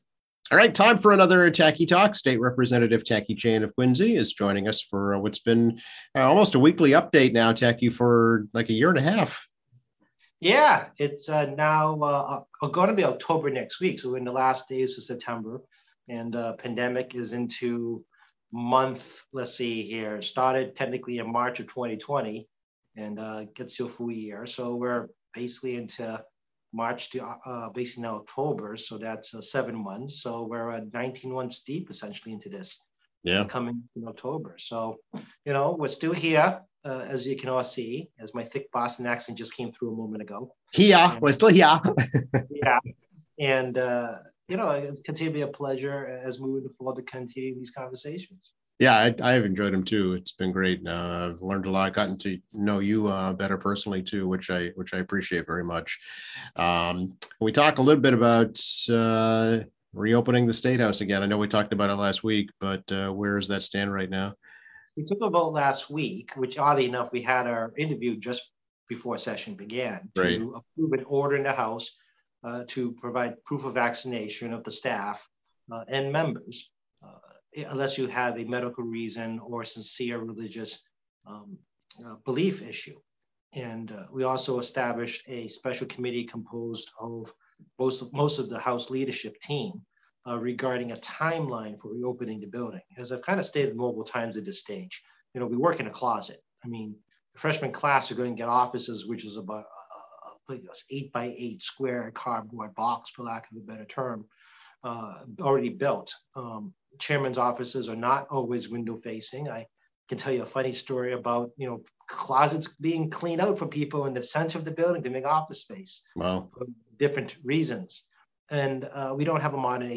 State Representative Tackey Chan, D - MA 2nd Norfolk District, chats about how the pandemic has impacted supply and demand, the budget process, and the State House reopening process.